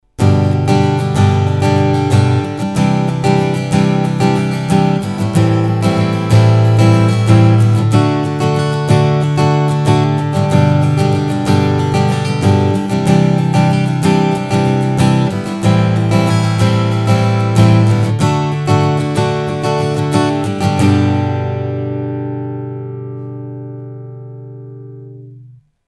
Pour mieux vous en rendre compte, j’ai effectué un petit enregistrement avec les deux sources simultanées (piezo et micro studio), et sur deux guitares différentes.
Tellement différent qu’il est d’usage en studio de mélanger les deux sources pour avoir plus de relief.
Guitare Lag 300DCE avec micro studio + micro piezo
lag_duo.mp3